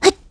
Gremory-Vox_Jump_kr.wav